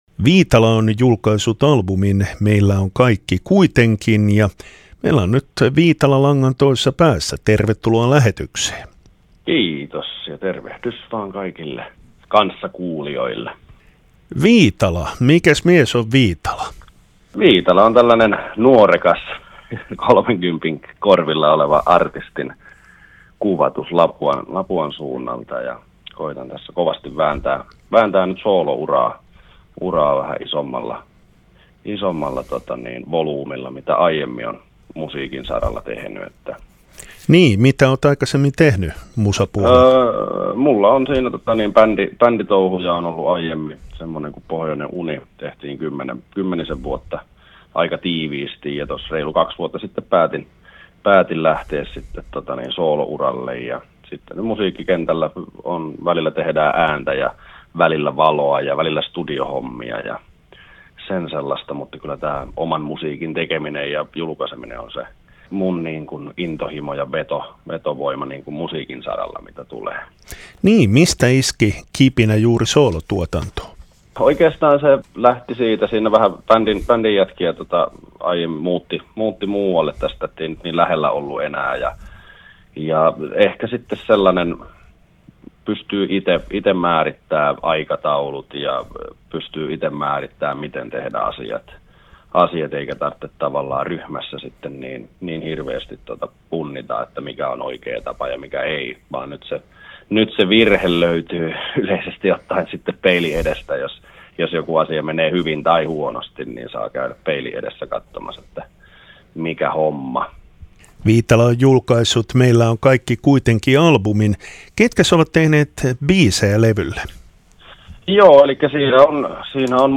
Musiikki Uusi albumi